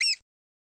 damaged.ogg